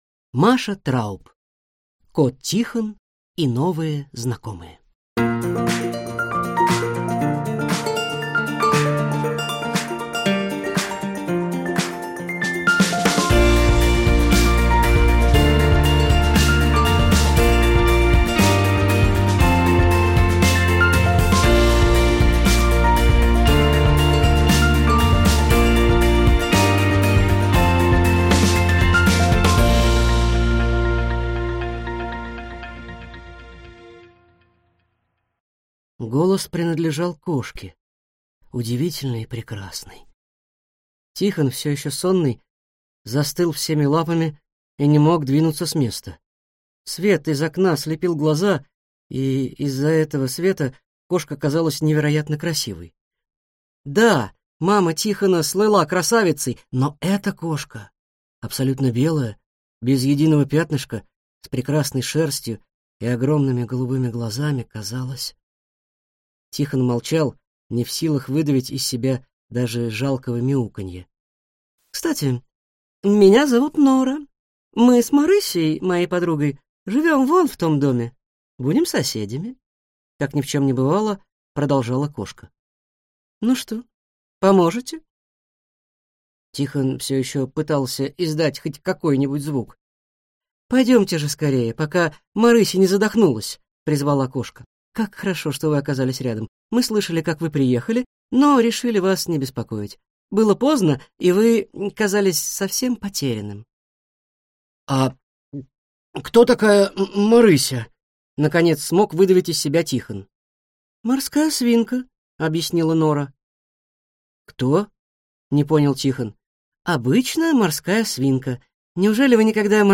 Аудиокнига Кот Тихон и новые знакомые | Библиотека аудиокниг